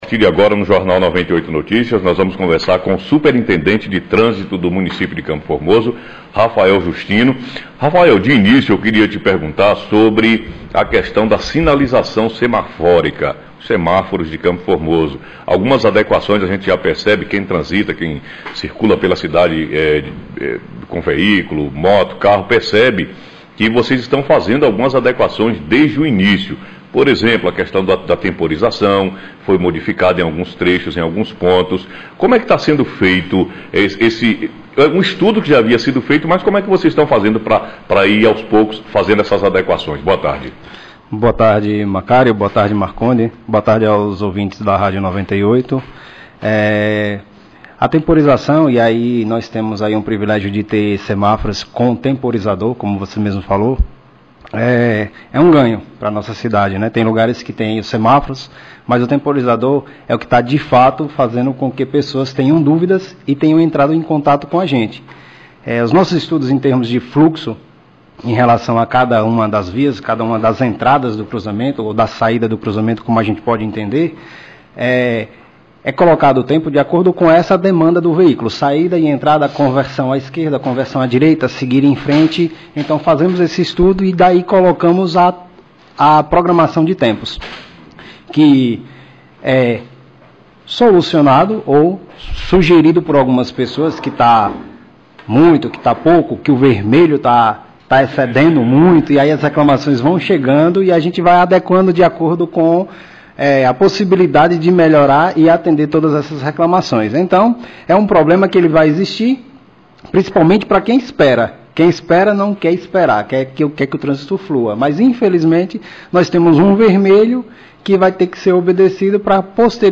Entrevista: Rafael Justino superintendente do trânsito de CFormoso